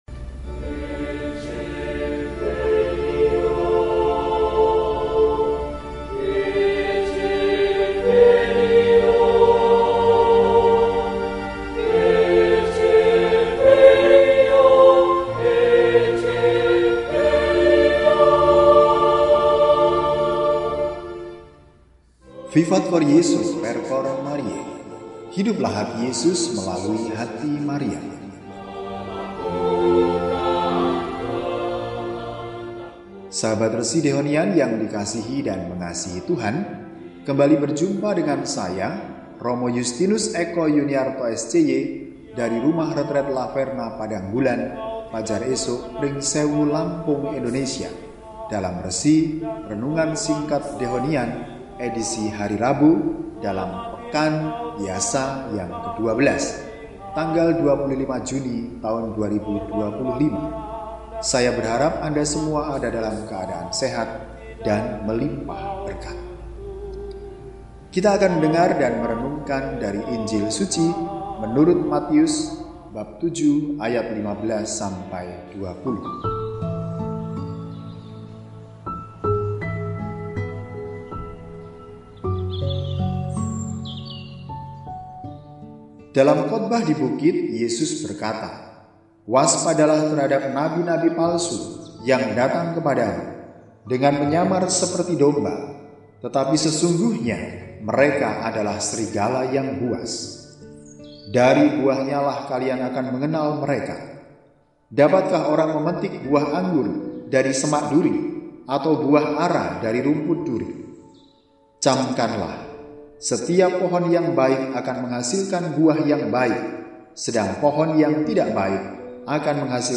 Rabu, 25 Juni 2025 – Hari Biasa Pekan XII – NOVENA HATI KUDUS YESUS HARI VIII – RESI (Renungan Singkat) DEHONIAN